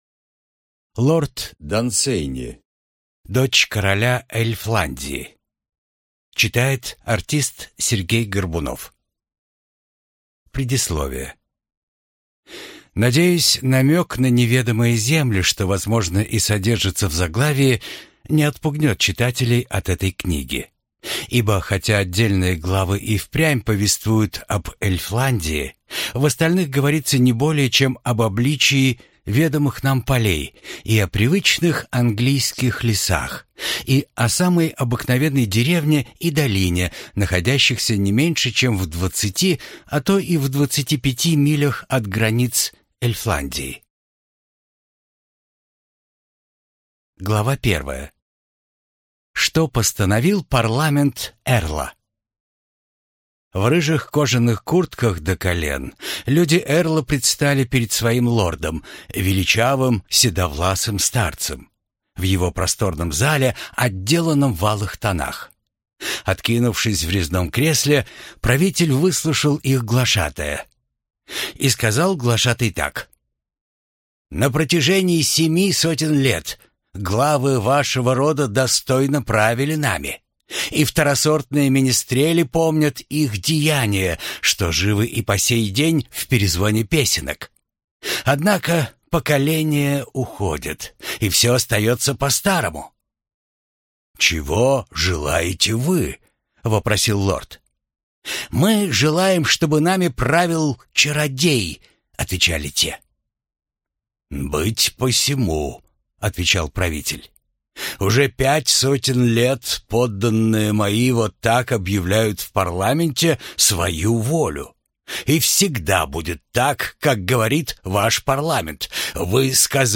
Аудиокнига Дочь короля Эльфландии | Библиотека аудиокниг